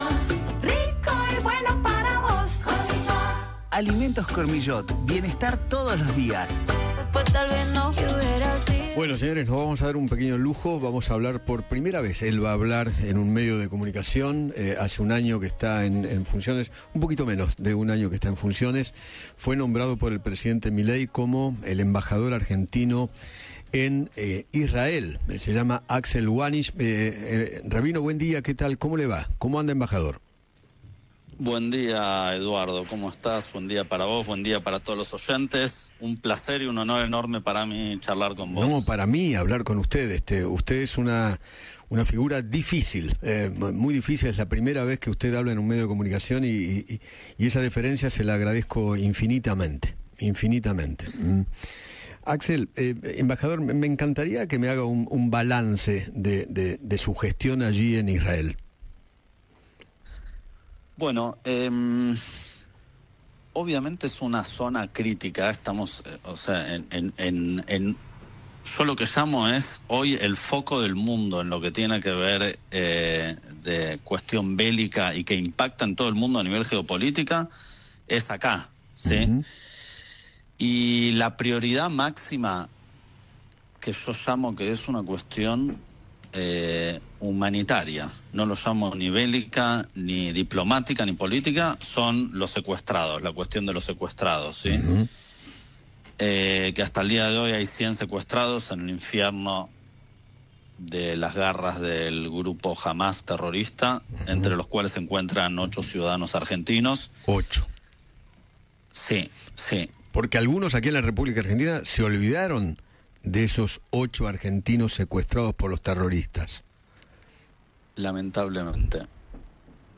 El embajador de Argentina en Israel Axel Wahnish conversó con Eduardo Feinmann sobre la relación entre ambos países y se refirió a la situación de los rehenes argentinos.